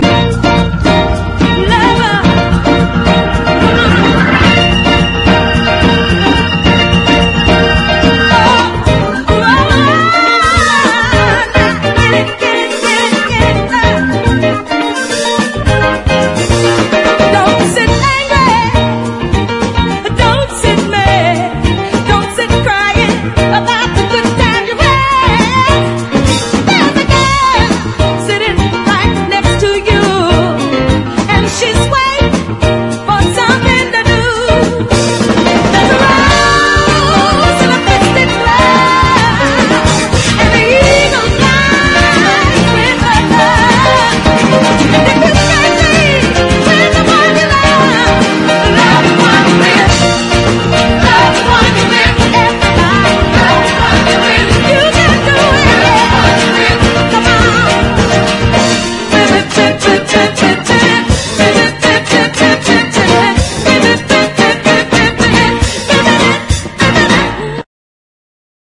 ROCK / 70'S / TEEN POP